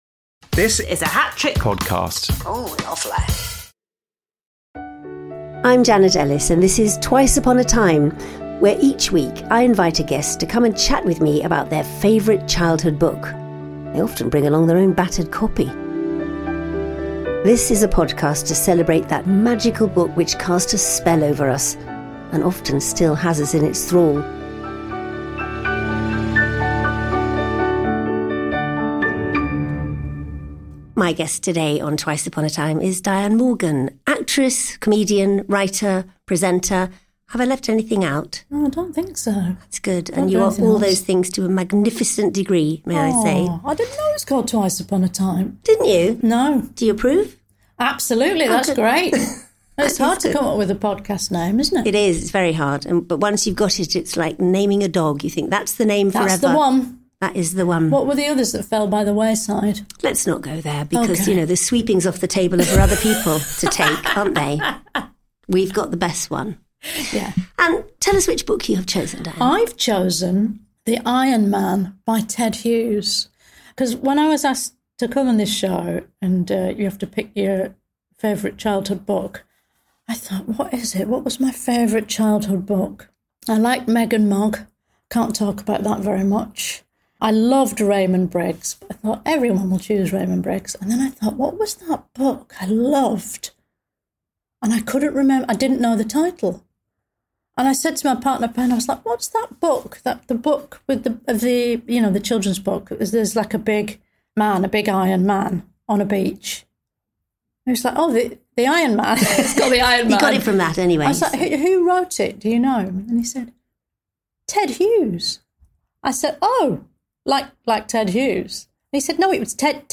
As you'd expect, a hilarious chat this week, partly due to Diane's reaction to re reading the book for the pod!